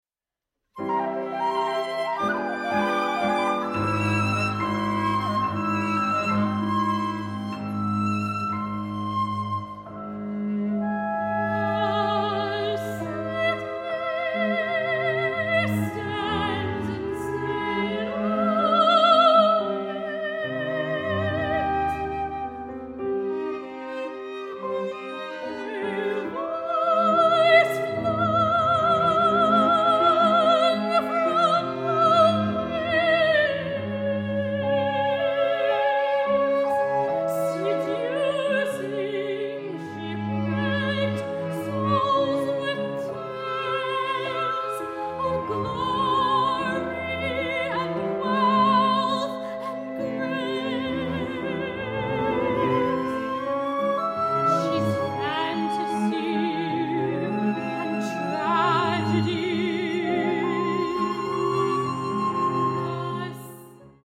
• Genres: Classical, Opera, Chamber Music
Recorded at Evelyn & Mo Ostin Music Center